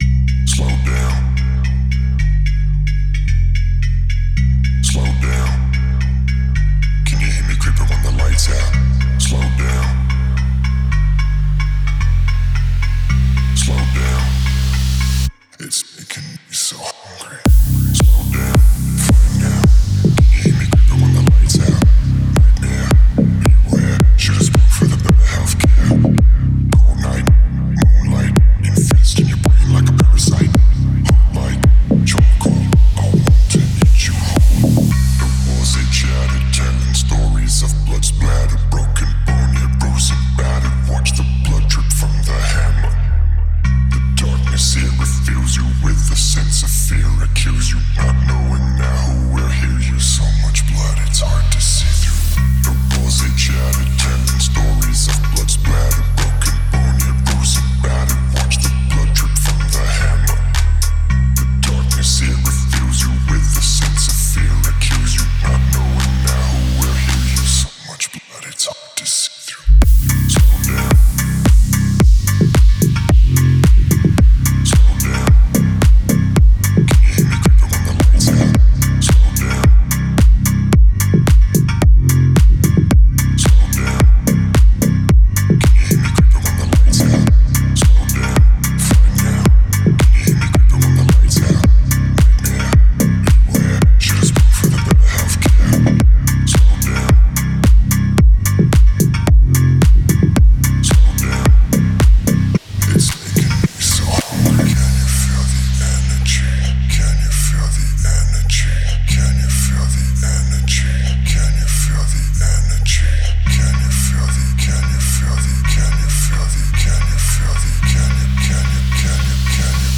это энергичная поп-рок композиция